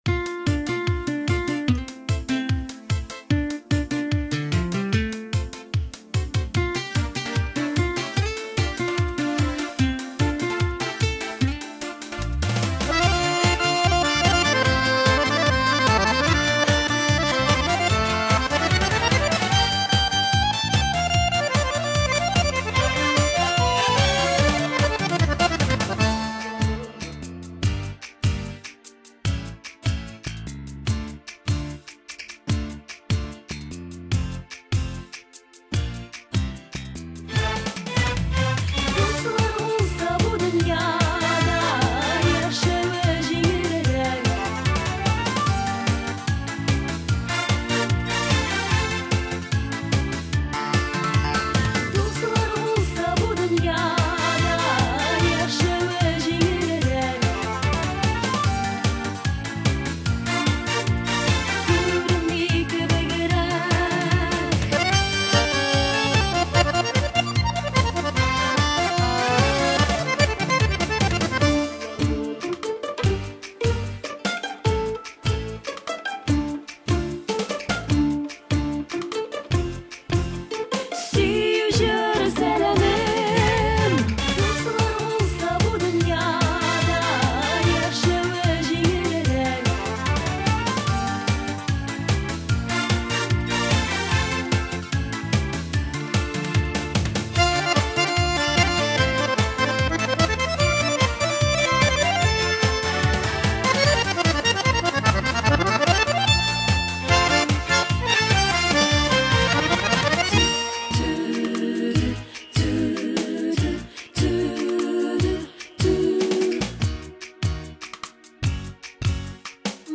минусовка